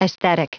Prononciation du mot aesthetic en anglais (fichier audio)
Prononciation du mot : aesthetic